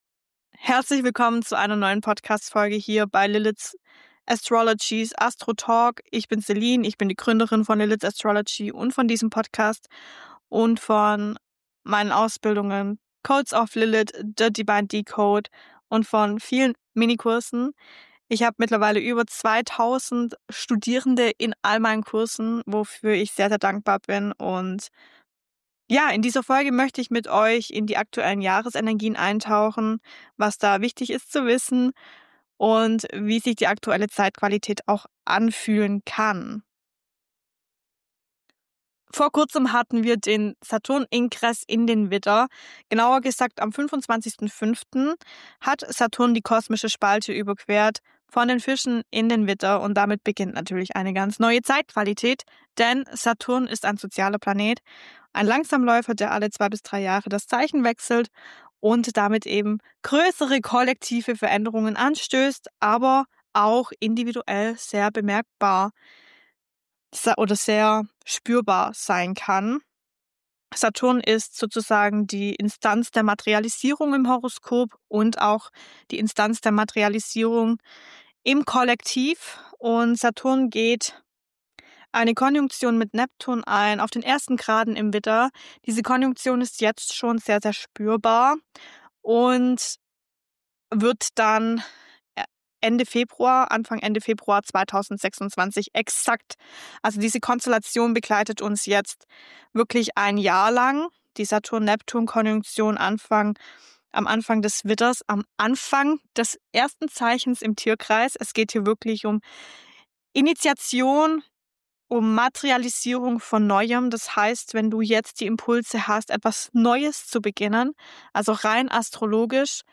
In dieser kraftvollen Solo-Folge von ASTRO TALK nehme ich dich mit in die aktuellen astrologischen Energien – und zwar tief.
Diese Folge ist frei gesprochen, direkt aus meinem Herzen, tief verbunden mit dem Kosmos und deinen Prozessen.